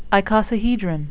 (i-co-sa-he-dron)